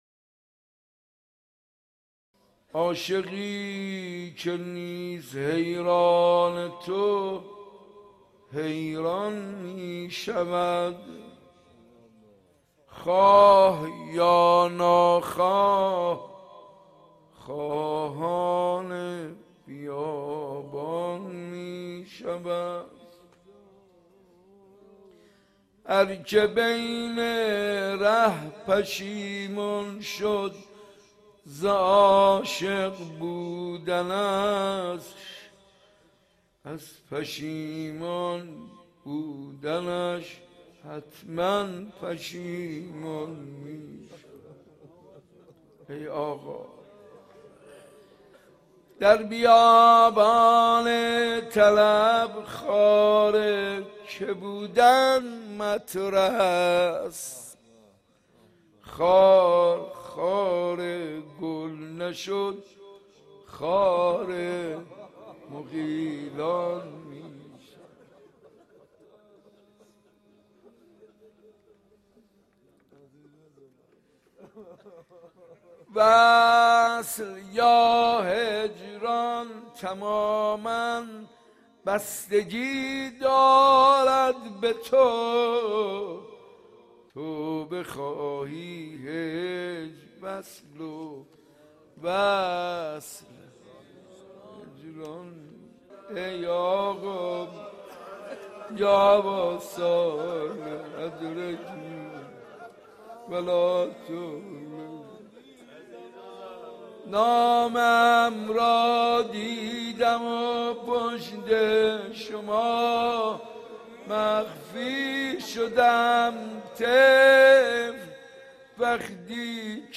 عاشقی که نیست حیران تو ؛ حیران می شود | مناجات با امام زمان
حسینیه ی صنف لباس فروش ها